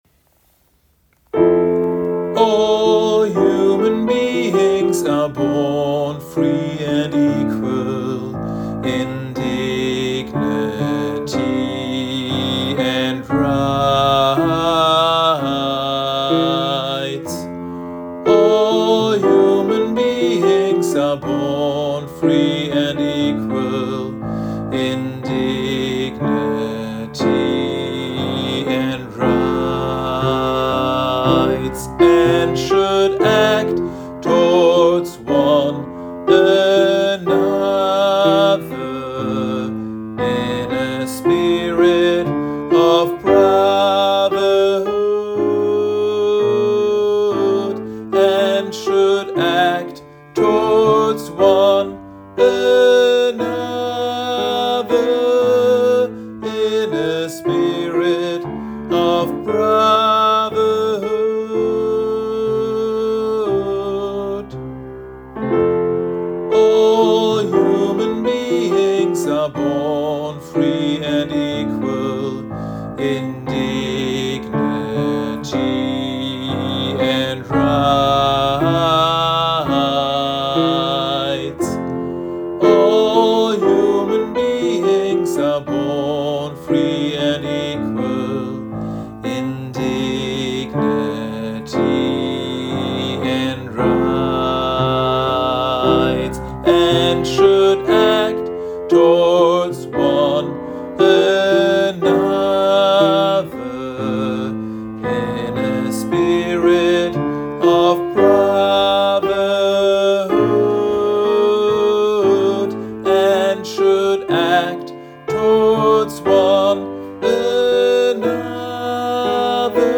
Übe-Dateien
MEZZO
Article01_Gospel_M.mp3